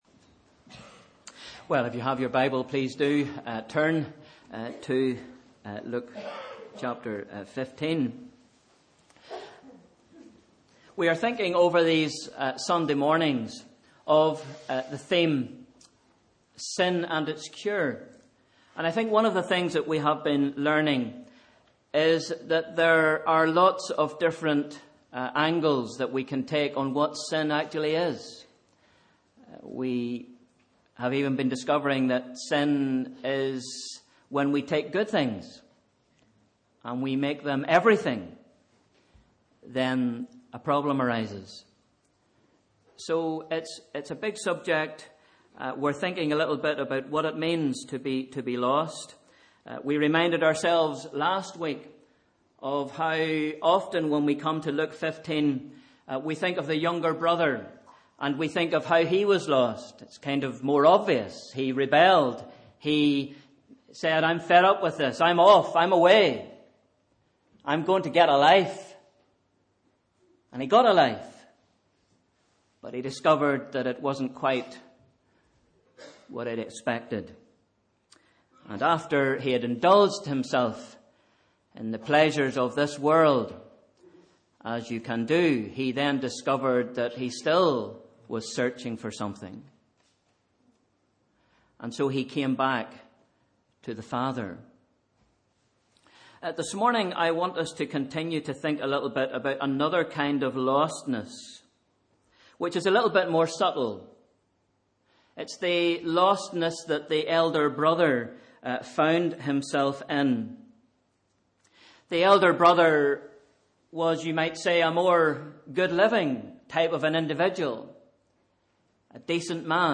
Sunday 28th February – Morning Service